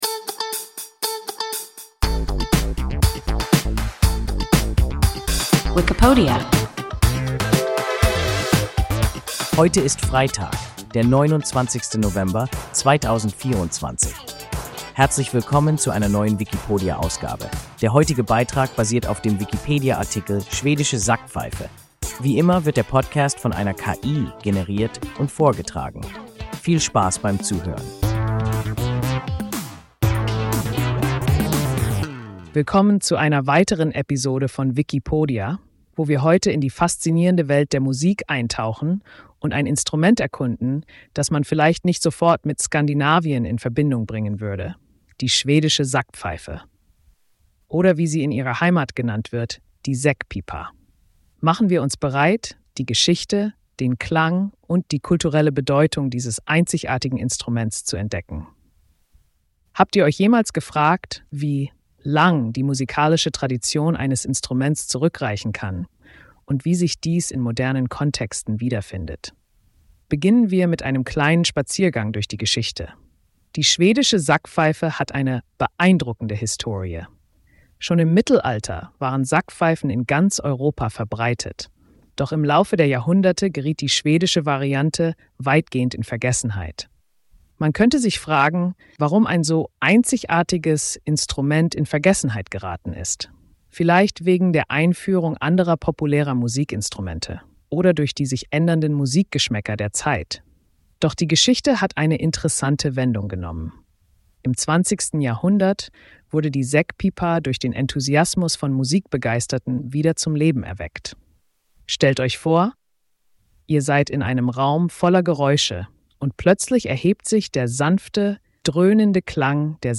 Schwedische Sackpfeife – WIKIPODIA – ein KI Podcast